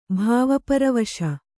♪ bhāva paravaśa